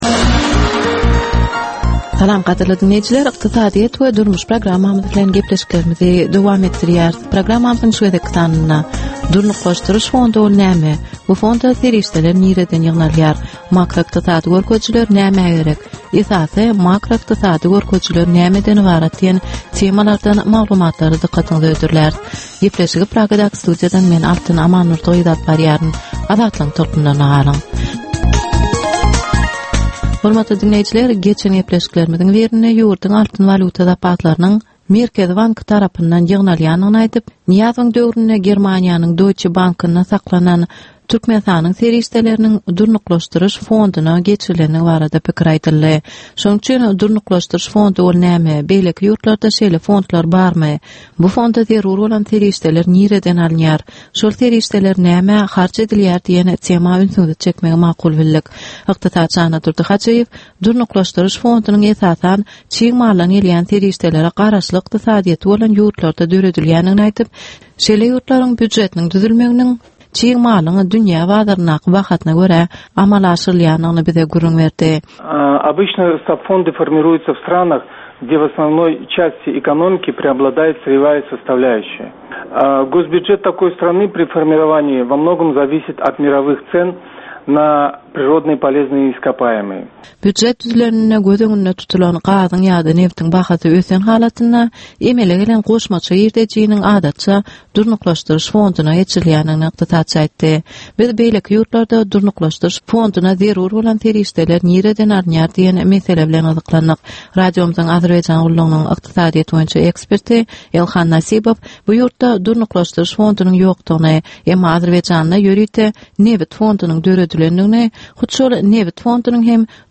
Türkmenistanyn ykdysadyýeti bilen baglanysykly möhüm meselelere bagyslanylyp taýýarlanylýan 10 minutlyk ýörite geplesik. Bu geplesikde Türkmenistanyn ykdysadyýeti bilen baglanysykly, seýle hem dasary ýurtlaryñ tejribeleri bilen baglanysykly derwaýys meseleler boýnça dürli maglumatlar, synlar, adaty dinleýjilerin, synçylaryn we bilermenlerin pikirleri, teklipleri berilýär.